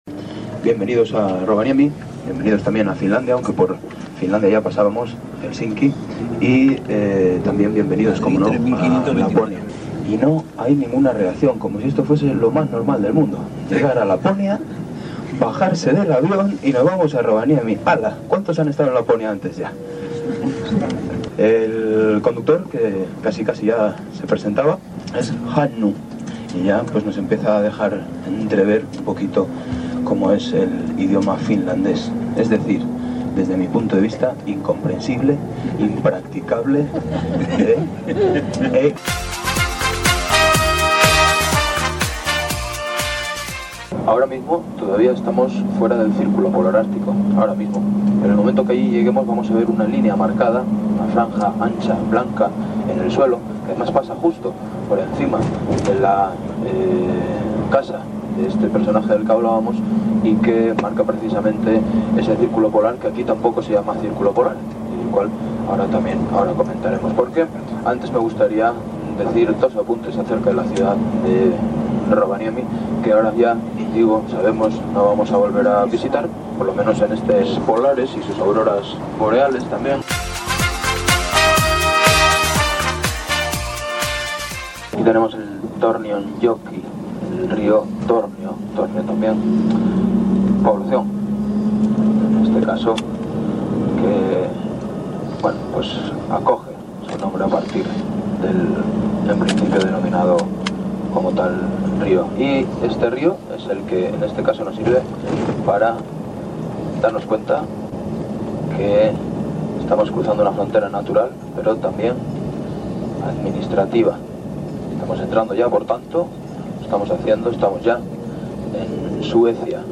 Hemos podido escuchar lo que cuenta un guía a un grupo de turistas al llegar a Rovaniemi, (el pueblo de Papa Noel) en Finlandia…